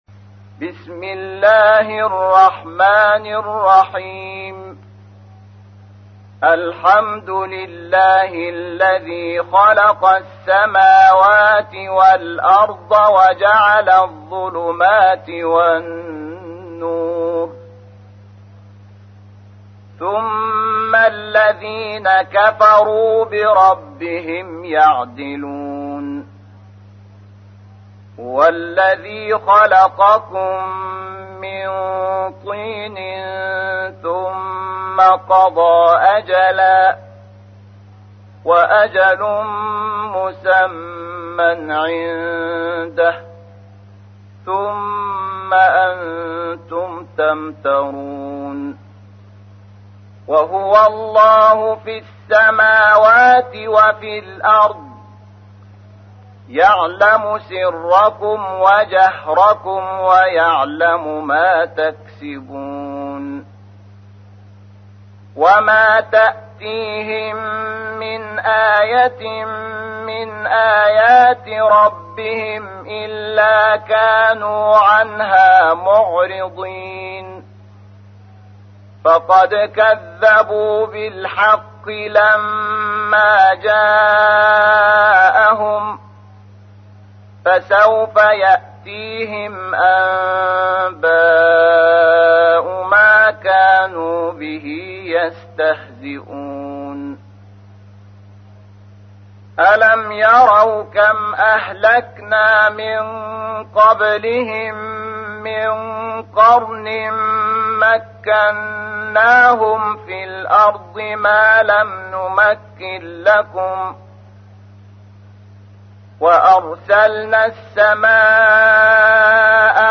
تحميل : 6. سورة الأنعام / القارئ شحات محمد انور / القرآن الكريم / موقع يا حسين